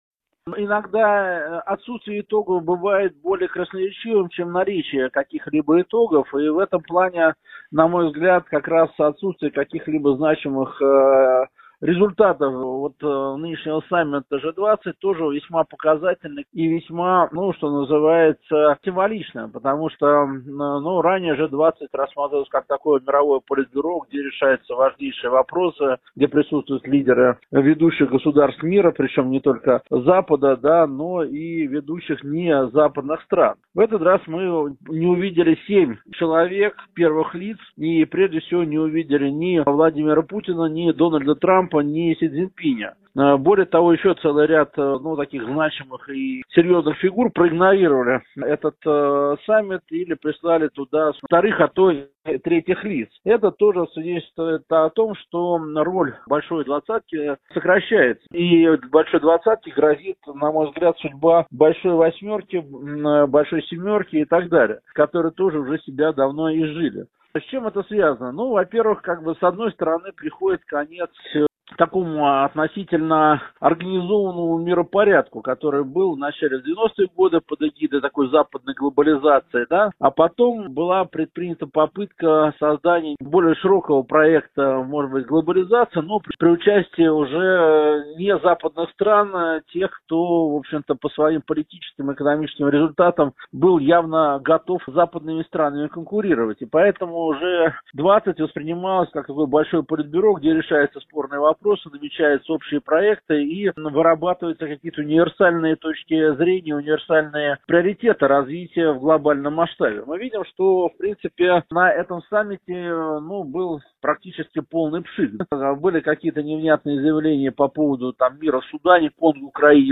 ГЛАВНАЯ > Актуальное интервью
в интервью журналу «Международная жизнь»